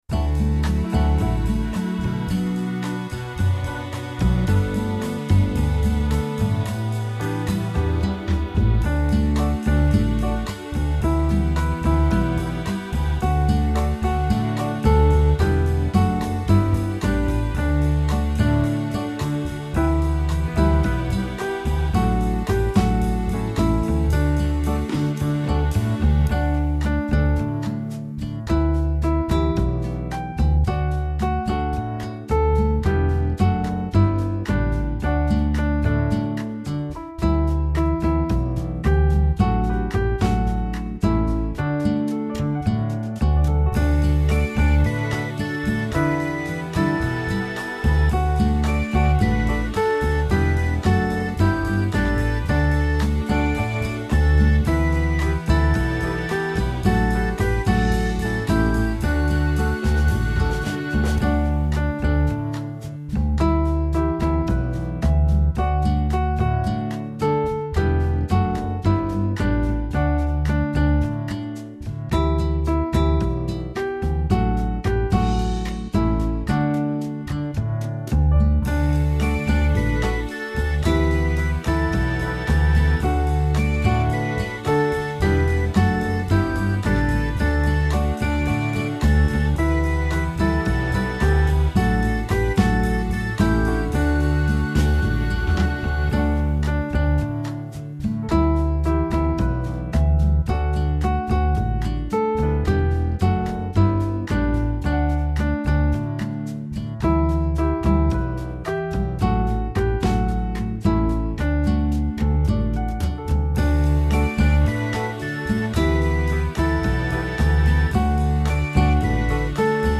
Pentecost song